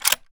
Reloading_begin0010.ogg